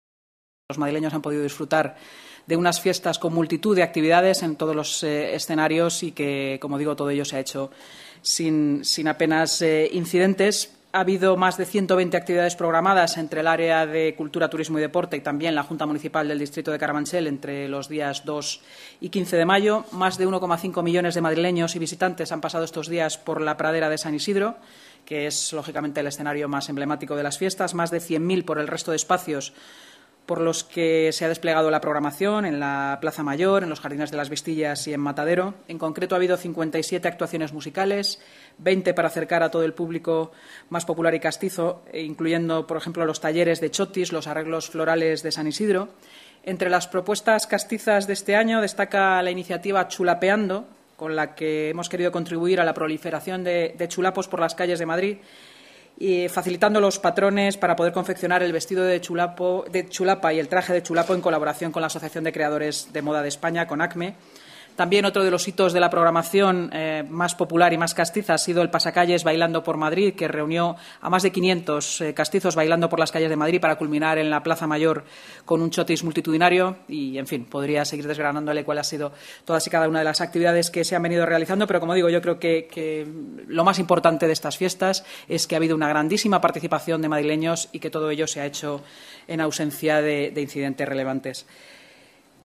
Nueva ventana:Inma Sanz, portavoz municipal: